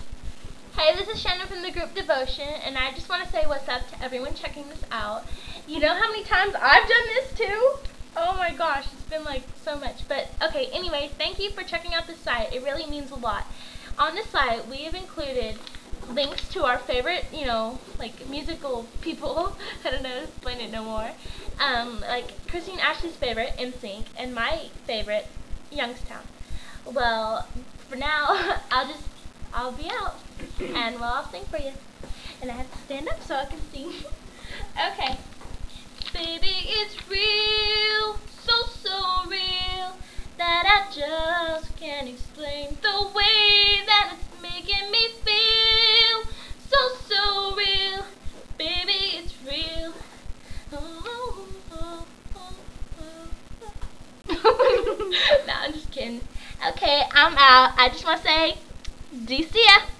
I held back a lot but for a different reason.
You can hear me going 'la la la' at the end, goofing off..ha ha ha